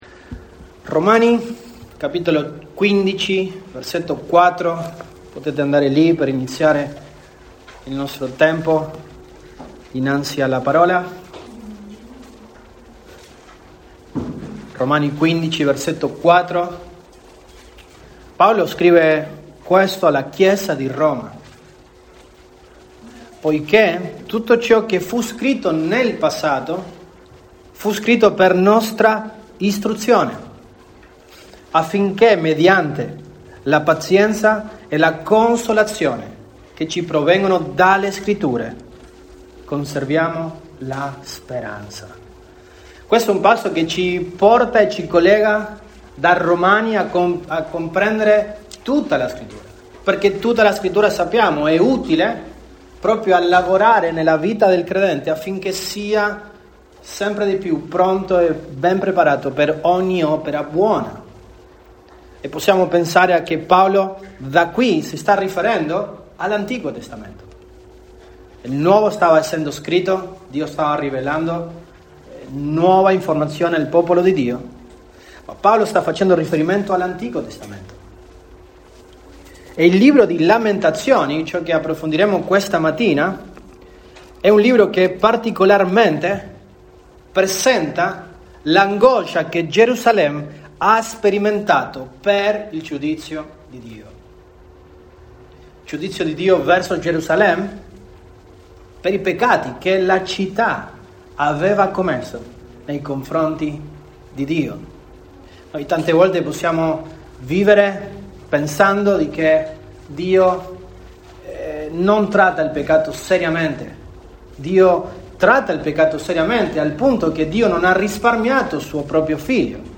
Set 03, 2023 Lamentazioni MP3 Note Sermoni in questa serie Panoramica sul libro di lamentazioni.